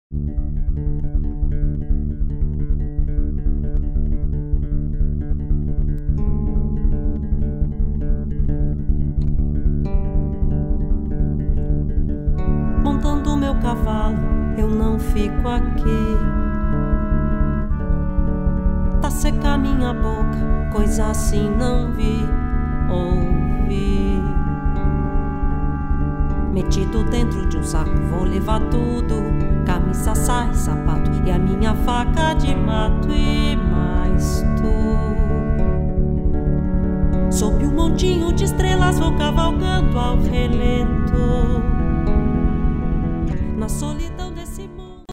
Voice
Bass 6 strings
Percussion
Keyboards